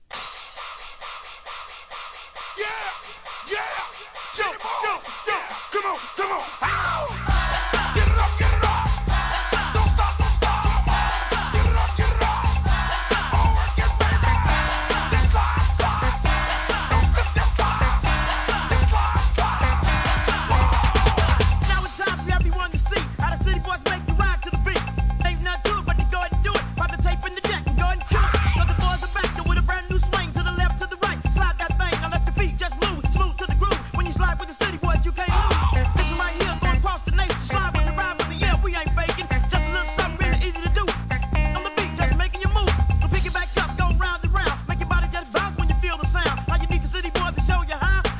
G-RAP/WEST COAST/SOUTH
マイアミ・サウンド!!